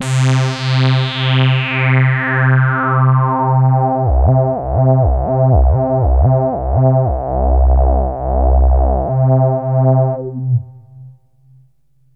SYNTH LEADS-2 0003.wav